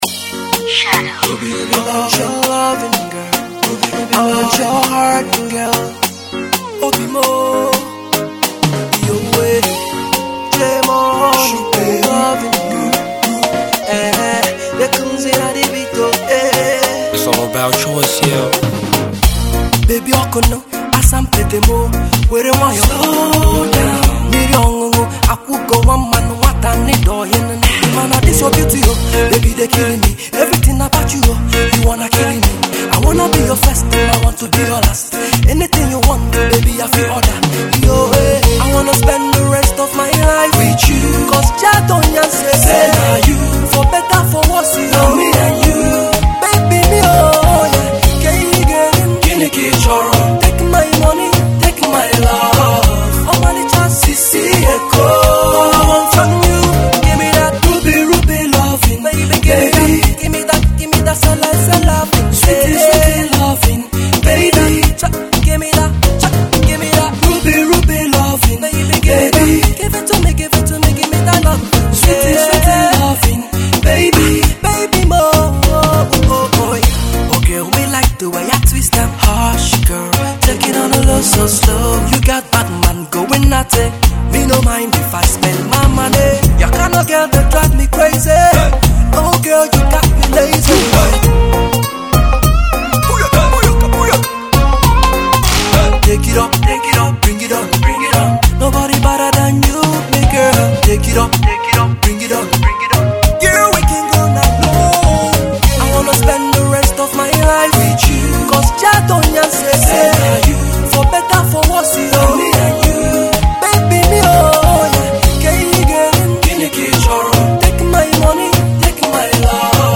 Pop 1 14 Enugu Based A…